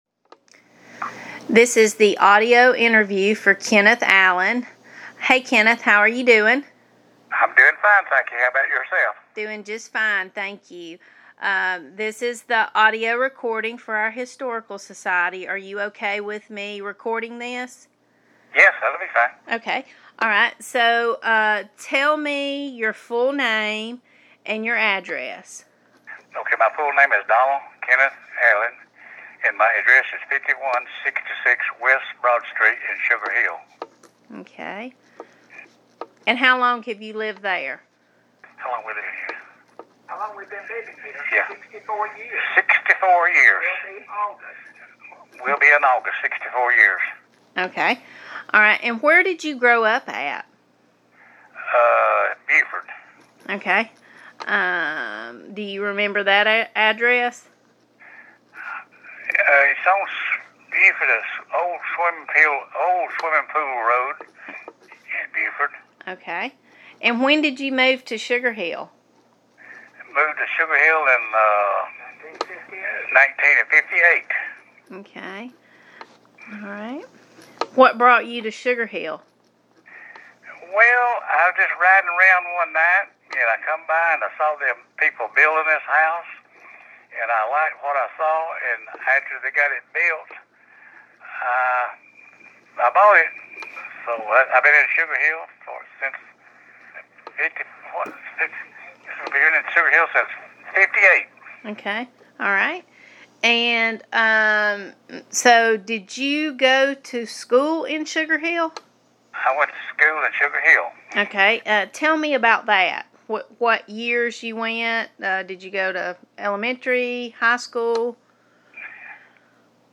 Oral histories
via telephone